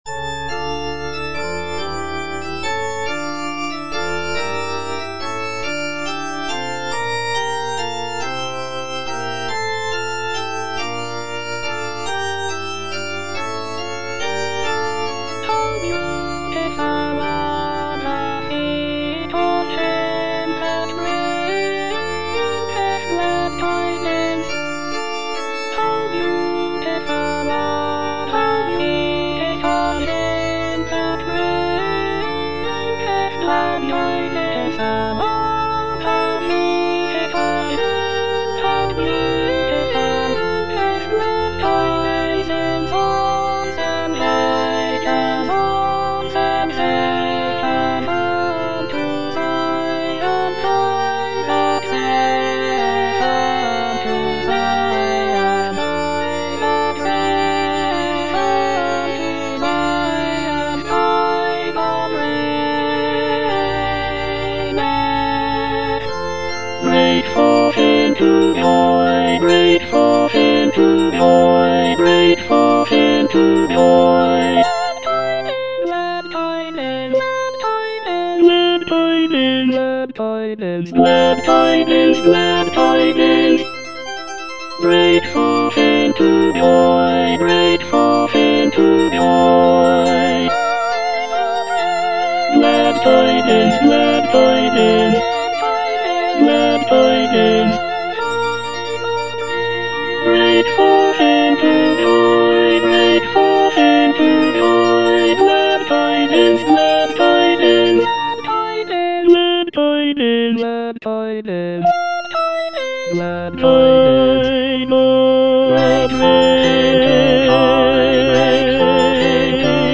G.F. HÄNDEL - HOW BEAUTIFUL ARE THE FEET OF HIM FROM "MESSIAH" (DUBLIN 1742 VERSION) Tenor (Emphasised voice and other voices) Ads stop:
The piece features a solo soprano or tenor singing about the beauty of the messengers who bring good news and preach the gospel of peace.